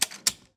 pistol Empty.wav